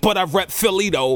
Hip-Hop Vocals Samples